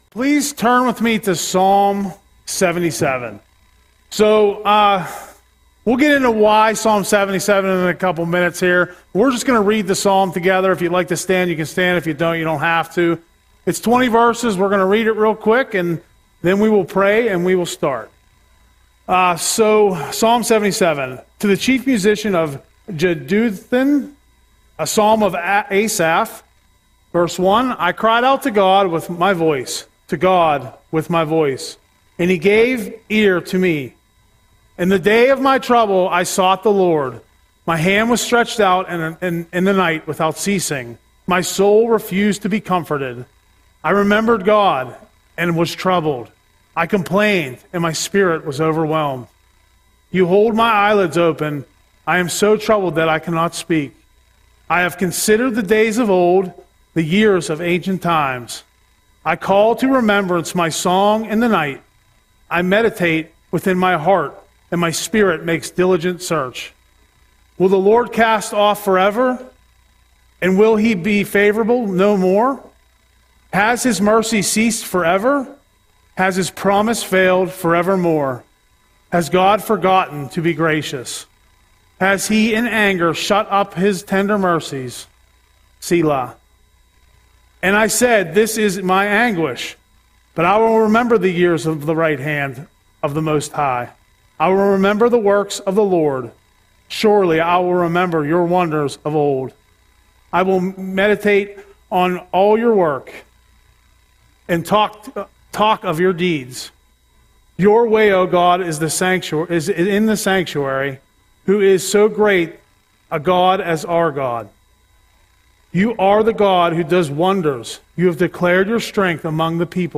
Audio Sermon - July 16, 2025